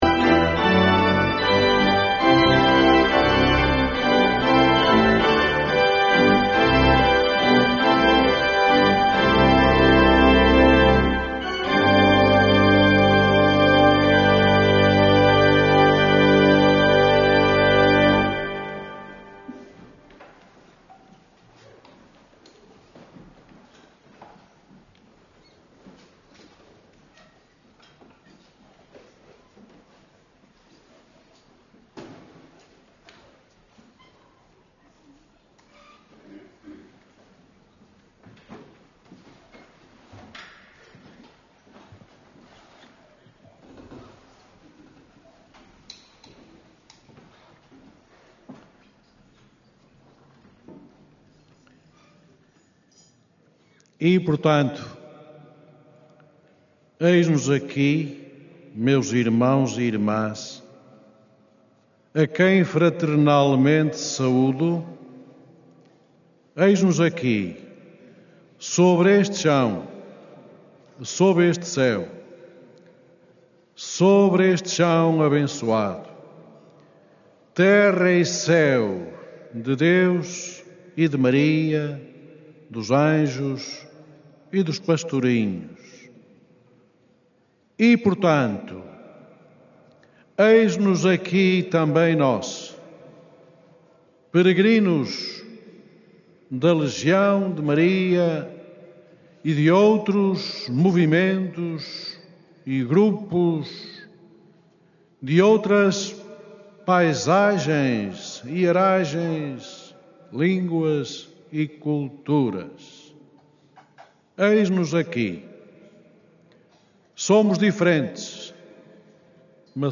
D. António Couto presidiu à missa deste domingo, em Fátima, que integrou a Peregrinação Nacional da Legião de Maria.
O bispo de Lamego, D. António Couto, presidiu à missa dominical esta manhã, no Recinto de Oração do Santuário de Fátima, e exortou os peregrinos ali reunidos em assembleia à oração com humildade e de forma empenhada.